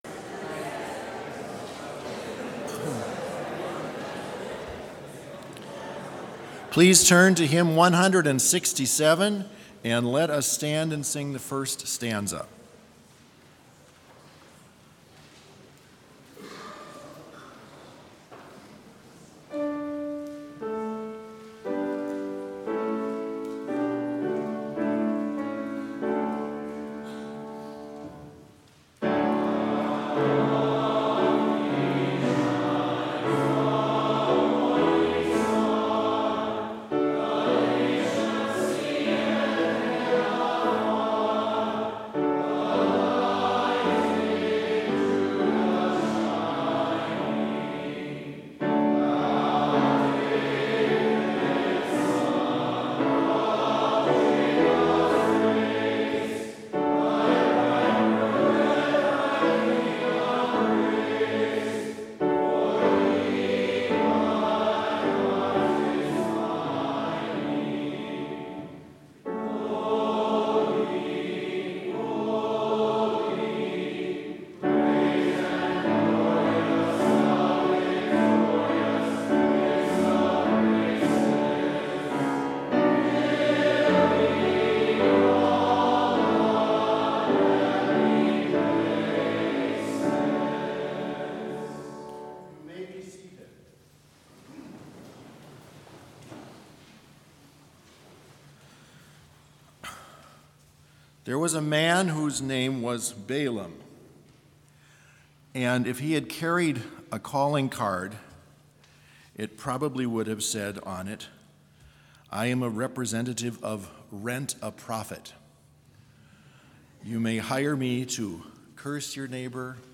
Complete service audio for Chapel - January 9, 2020